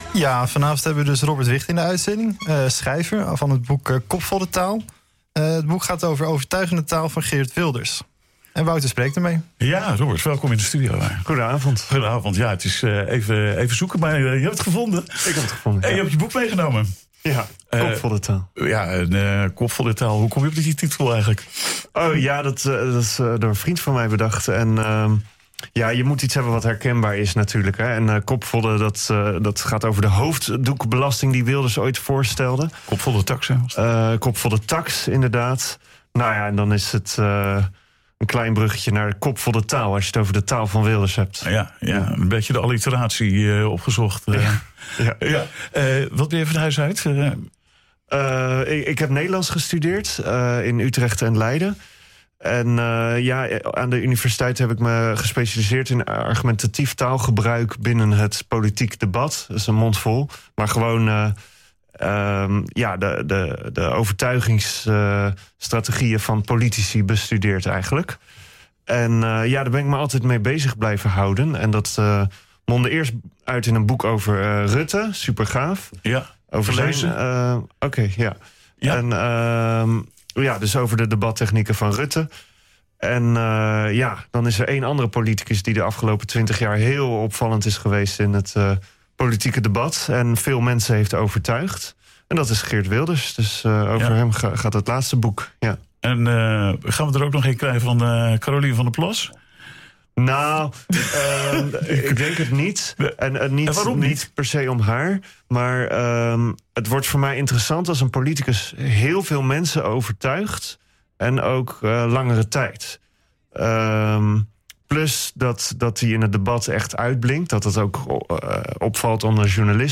Zaagmans In De Avond is het nieuws- en achtergrondprogramma van NHGooi dat wekelijks wordt uitgezonden op de woensdagavond tussen 19:00 en 20:00 uur.